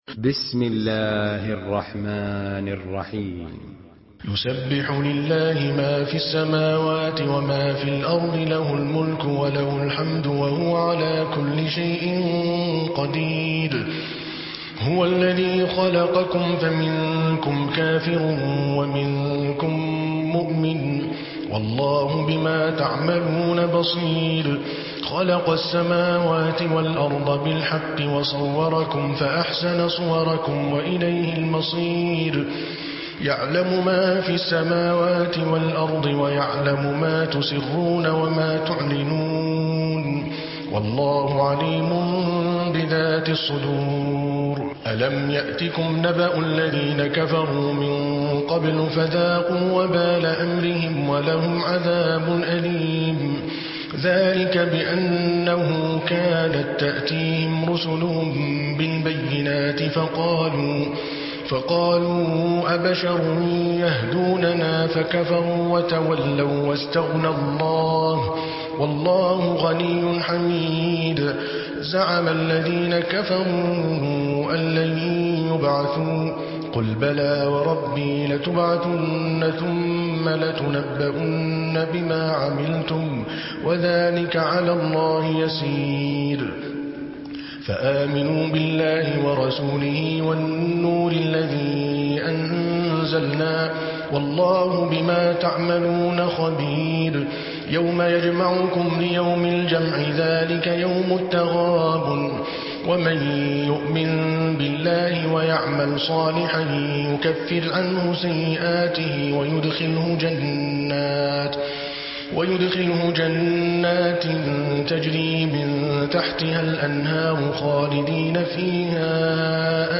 Surah Tegabun MP3 by Adel Al Kalbani in Hafs An Asim narration.
Murattal Hafs An Asim